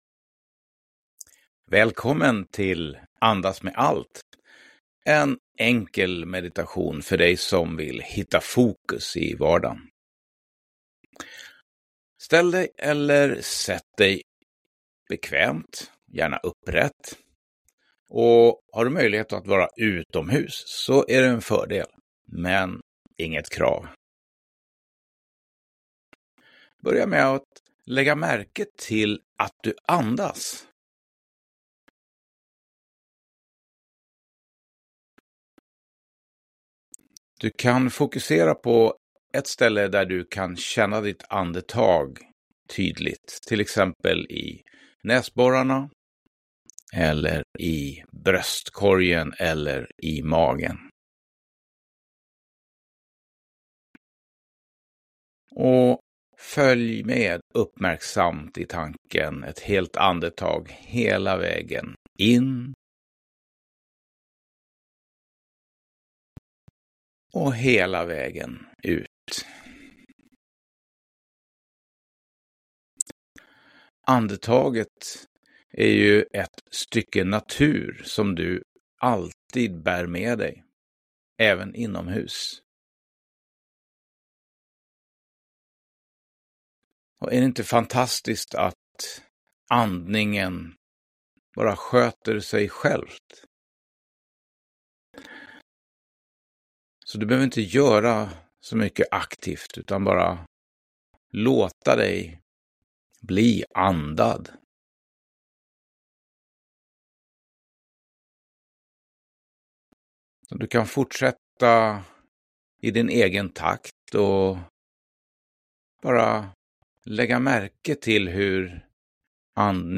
Andas med allt - meditation 10 min